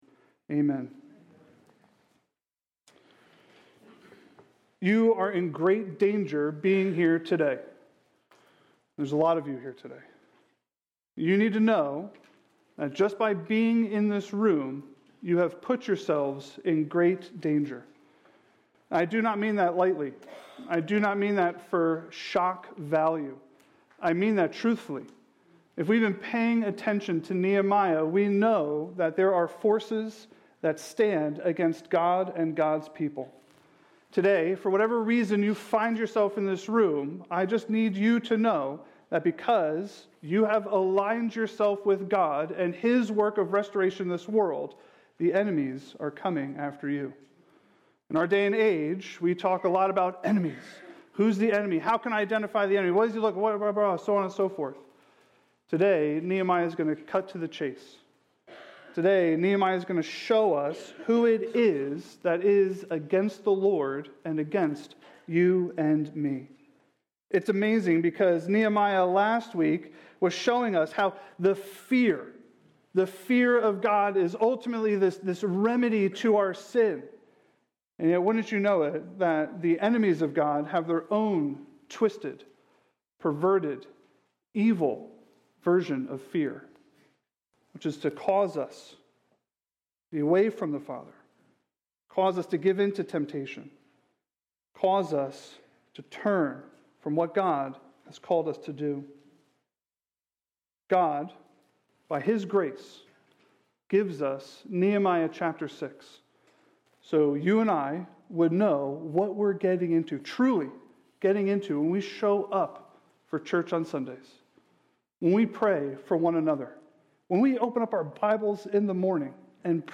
By Green Pond Bible Chapel Sermon